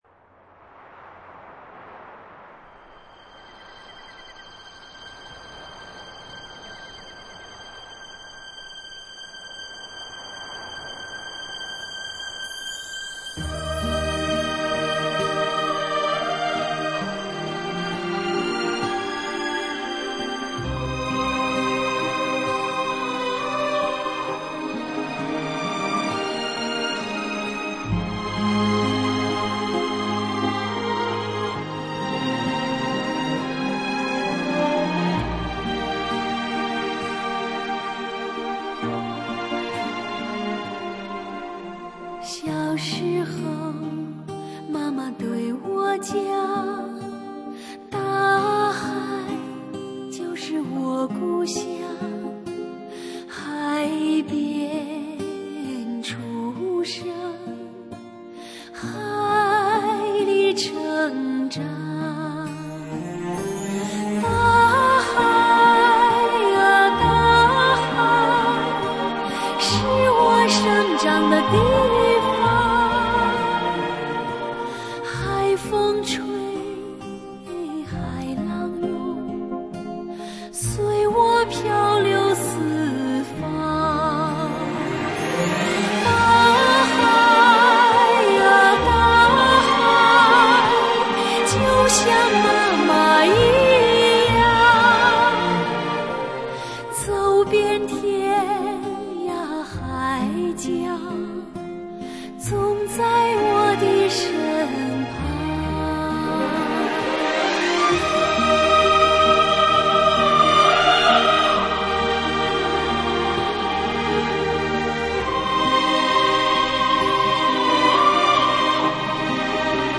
怀旧民歌，重温逝去的记忆，以最简单却动人的乐曲慰籍心灵。